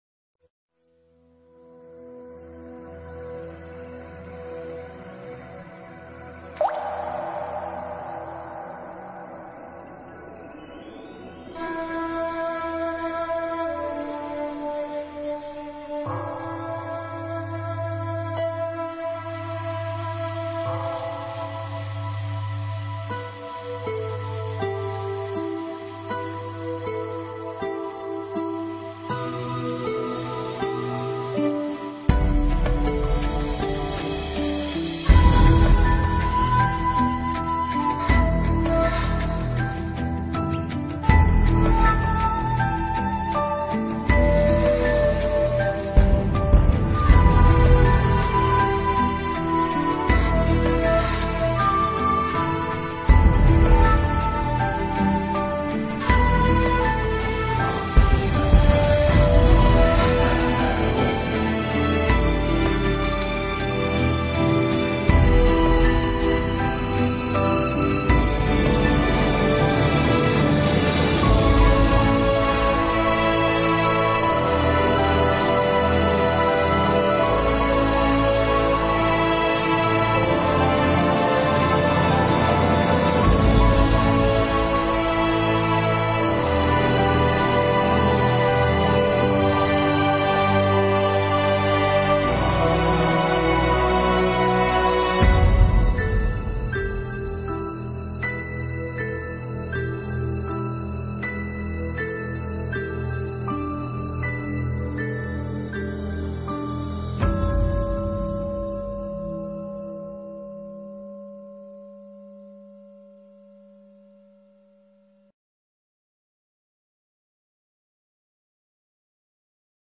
少林寺(上)--有声佛书